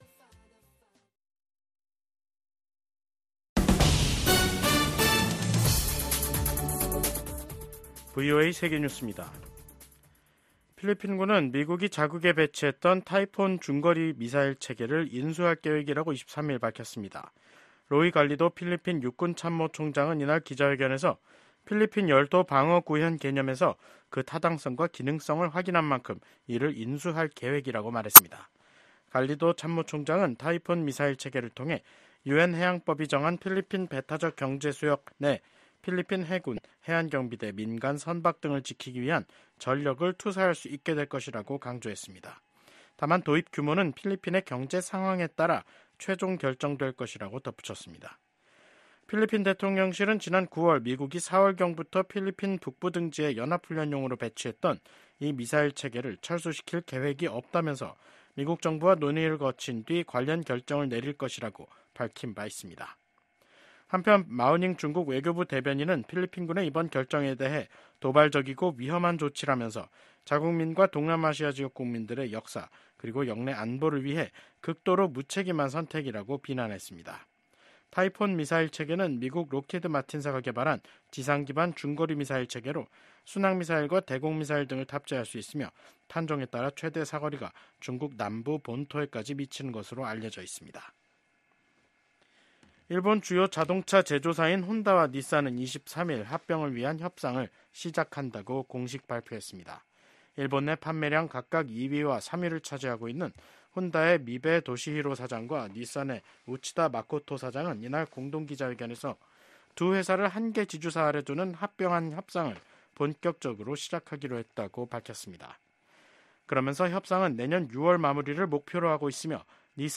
VOA 한국어 간판 뉴스 프로그램 '뉴스 투데이', 2024년 12월 23일 3부 방송입니다. 한국 군 당국은 북한이 러시아에 추가 파병을 하고 자폭형 무인기 등을 지원하려는 동향을 포착했다고 밝혔습니다. 대북송금 사건 공모 혐의로 최근 한국 정계 인사가 항소심에서 실형을 선고받은 가운데 미국 국무부는 각국의 대북제재 이행의 중요성을 강조했습니다. 중국을 견제하기 위한 조선업 강화 법안이 미국 의회에 초당적으로 발의됐습니다.